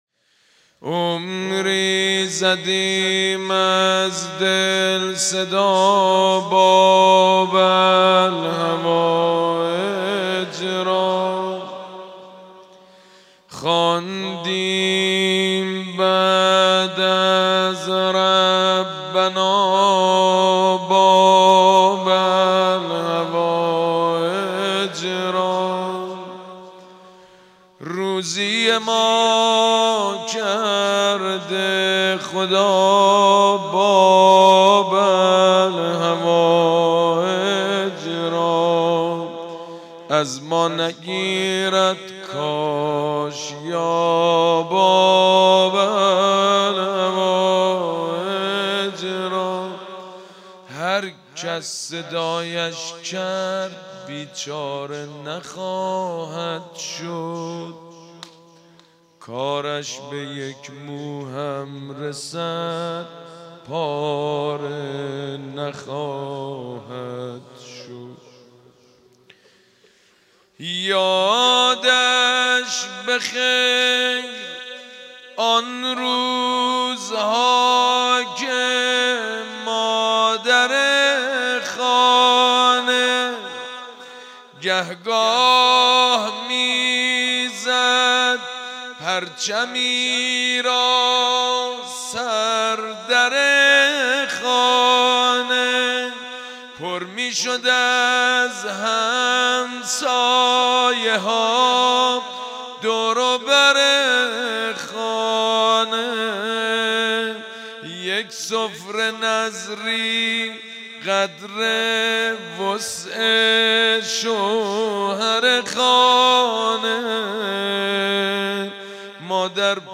با اینکه پیکر پسرش بوریا شود روضه محمود کریمی
روضه.mp3